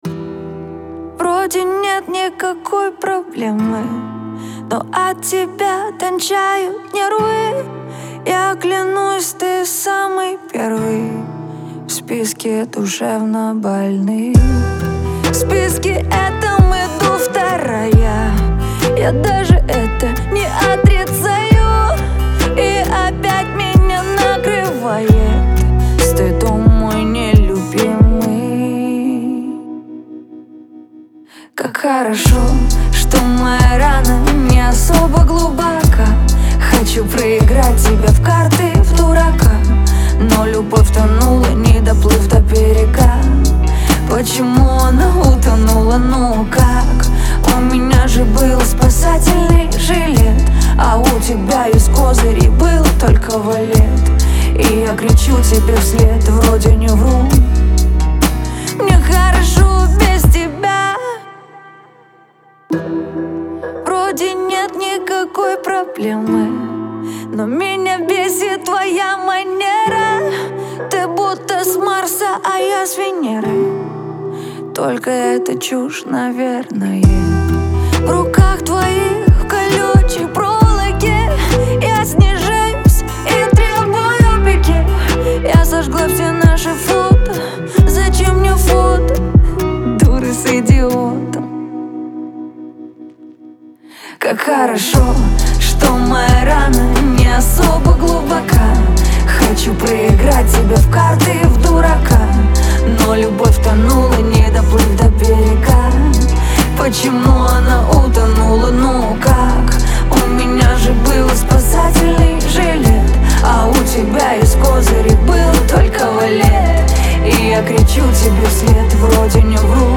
яркая поп-песня
наполненная энергией и позитивом.
мощный вокал и эмоциональную подачу